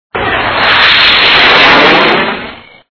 軍事(shì)飛(fēi)機(jī)的(de)聲音(yīn)。
战斗机.mp3